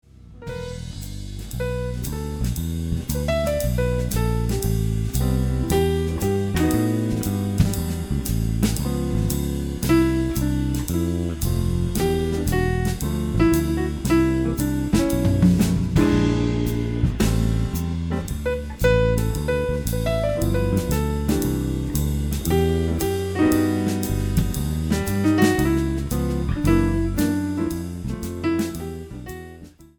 原曲のメロディーを残しながらも、見事なまでのジャズ・ナンバーに仕上げている。